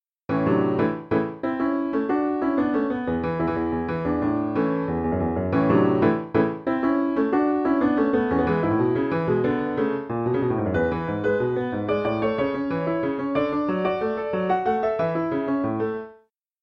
I sit at the piano and just play what feels right.
This looser method is how I came up with this rock-infused version of the theme in 4/4 time: